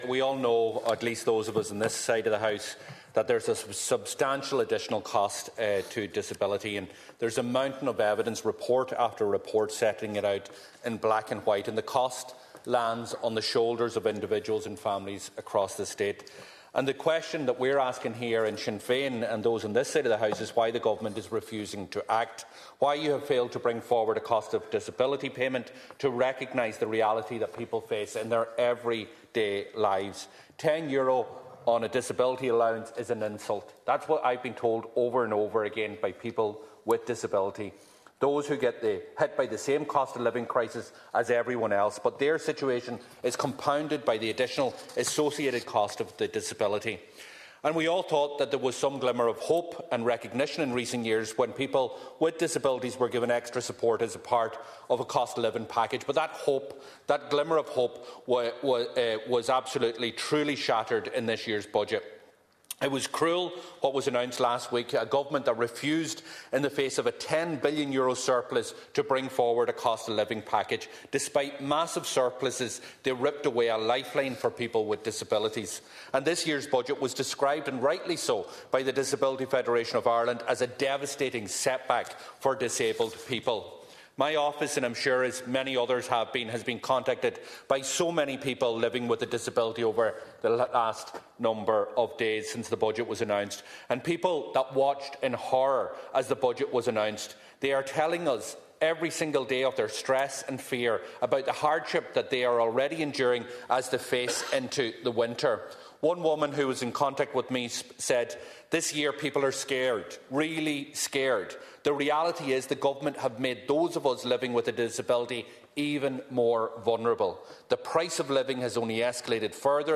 During a Dail debate last night, Donegal Deputy Pearse Doherty said the €10 disability allowance increase is pitiful and will be eaten up by the cost of living crisis.